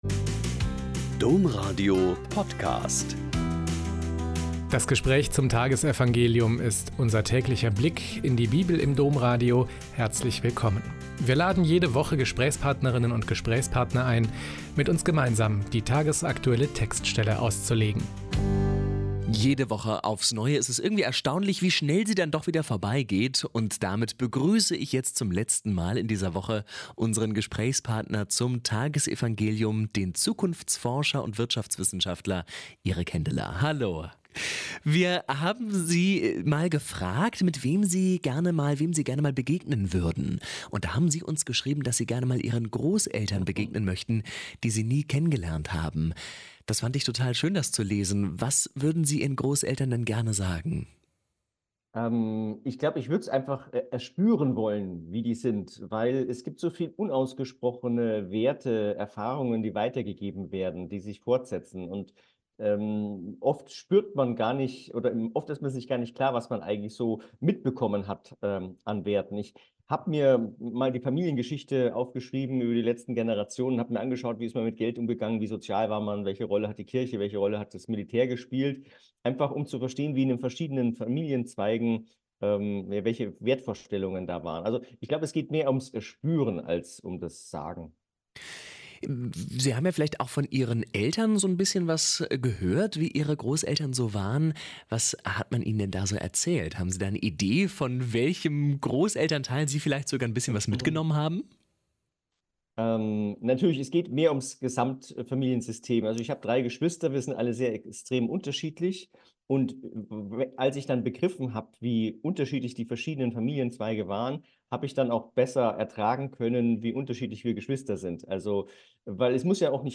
Lk 12,8-12 - Gespräch